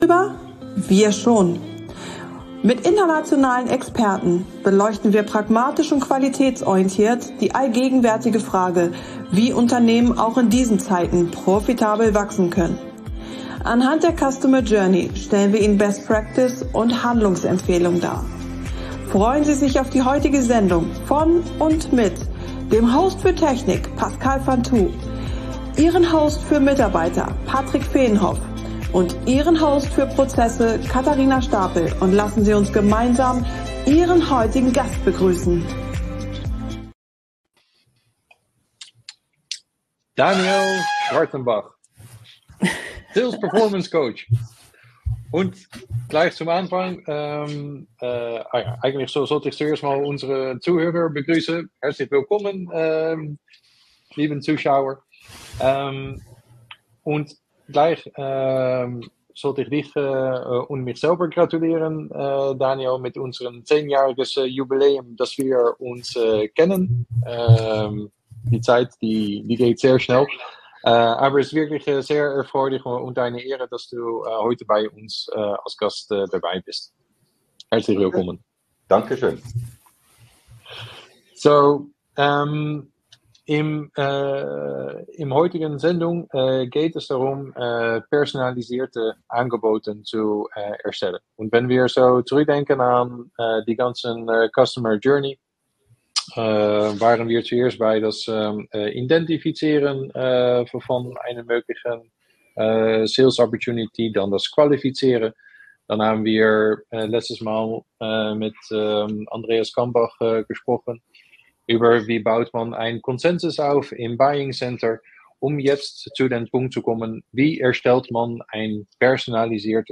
Offer – create a personalised offer - Impulsdiskussion